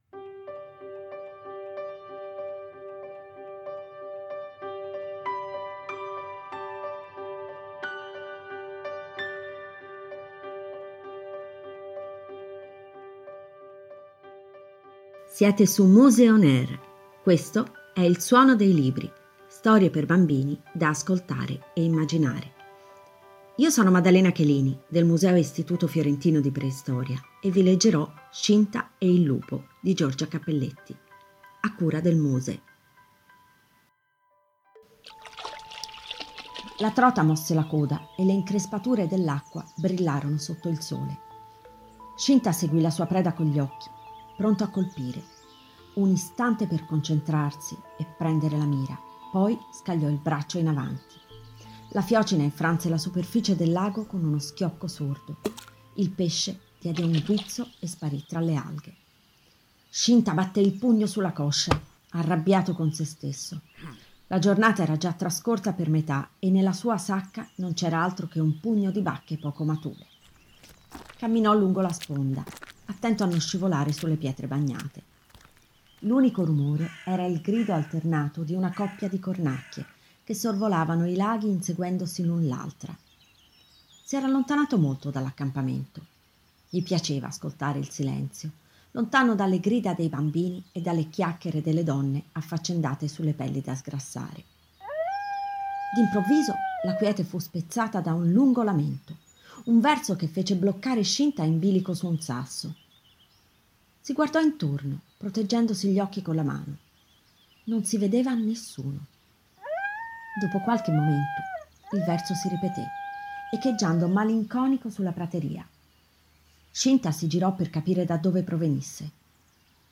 Audio-libro-Shinta-e-il-Lupo.mp3